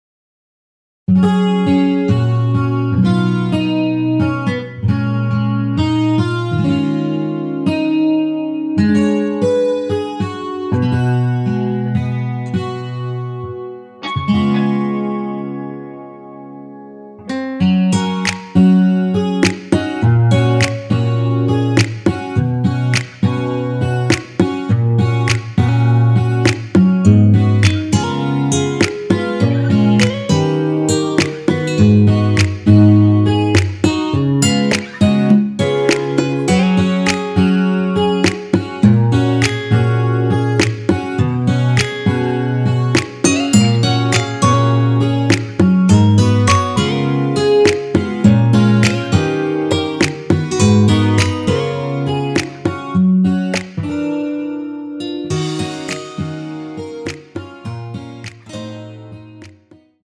엔딩이 페이드 아웃이라 라이브 하시기 좋게 엔딩을 만들어 놓았습니다.
원키에서(+3)올린 MR입니다.
F#
앞부분30초, 뒷부분30초씩 편집해서 올려 드리고 있습니다.
중간에 음이 끈어지고 다시 나오는 이유는